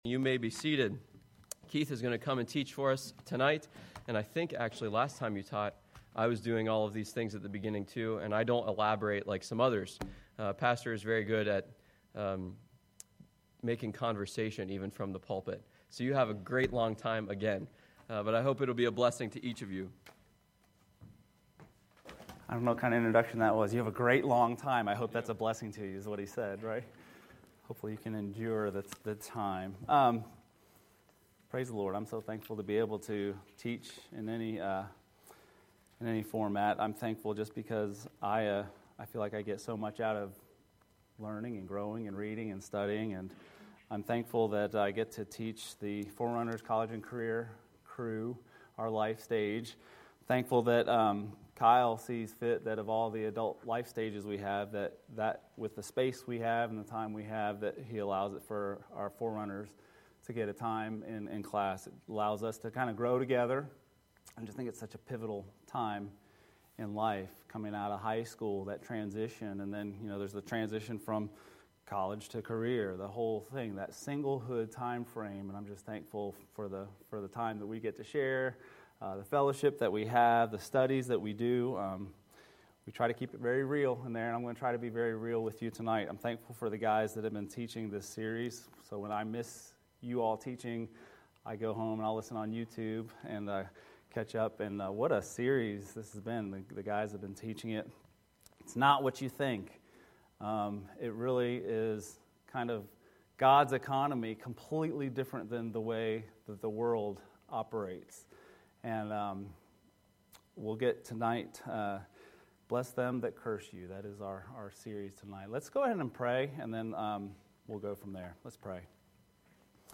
Sermons by Bluegrass Baptist Church